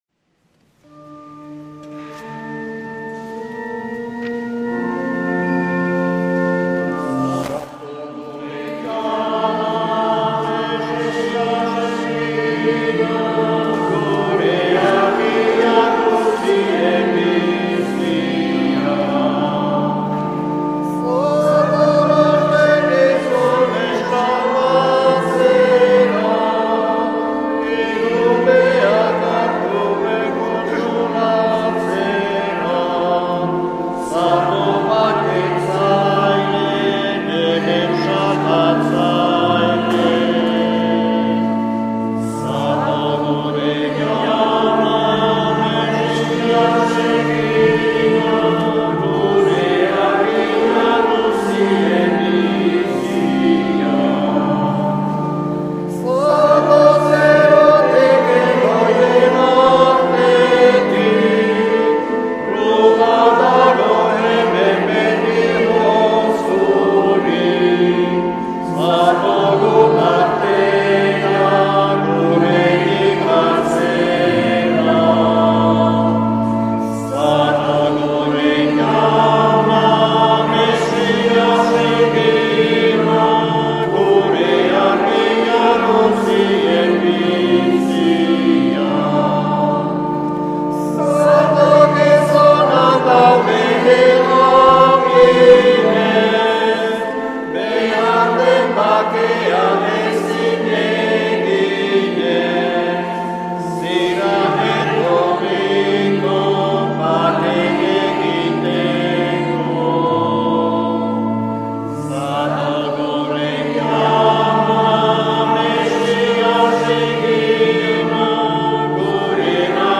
Accueil \ Emissions \ Vie de l’Eglise \ Célébrer \ Igandetako Mezak Euskal irratietan \ 2024-12-01 Abenduko 2.